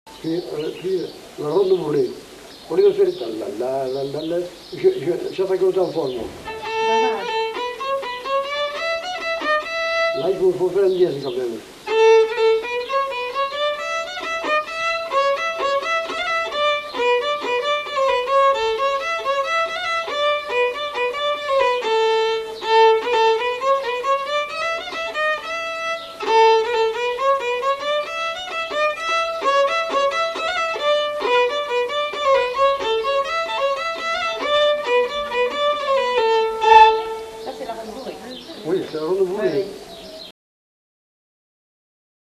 Aire culturelle : Couserans
Genre : morceau instrumental
Instrument de musique : violon
Danse : bourrée